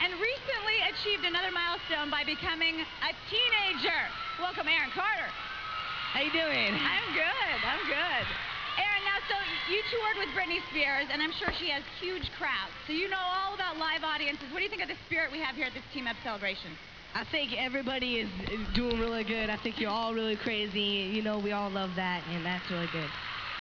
Sounds Recorded From TV Shows
I apologize for the quality of the sounds as I was not able to directly line-in record them, so they are slightly fuzzy
Aaron greets the crowd @ the assembly